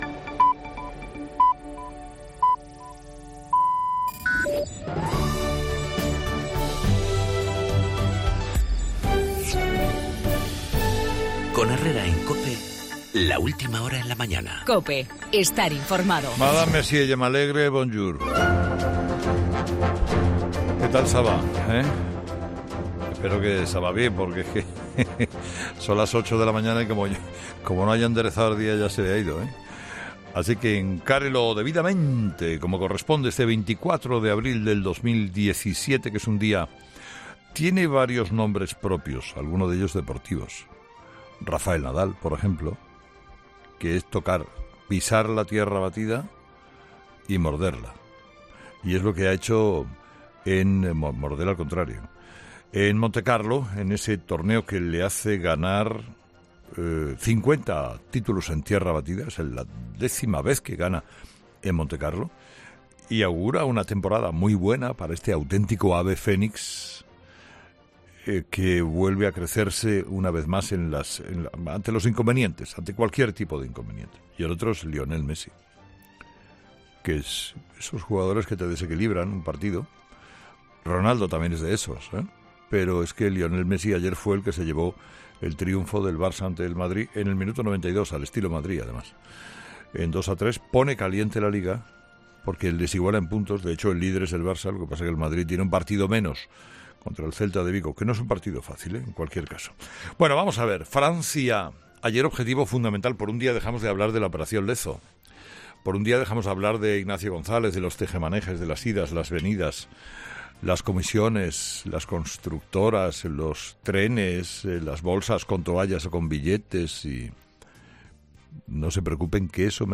Monólogo de las 8 de Herrera